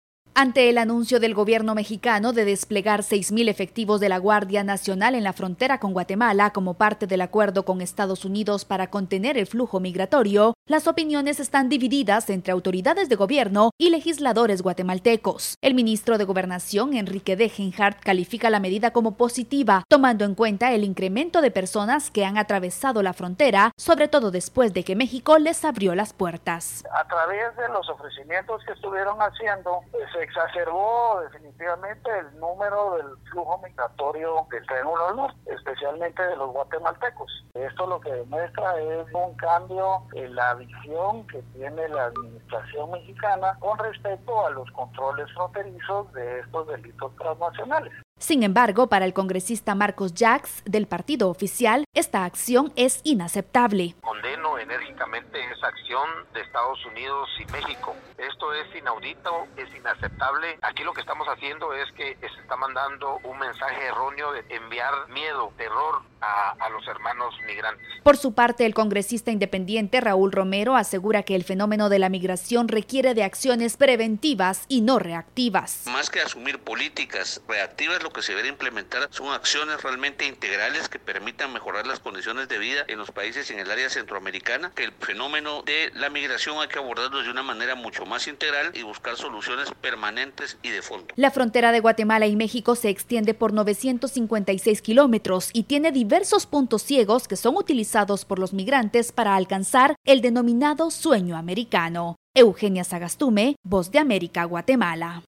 VOA: Informe desde Guatemala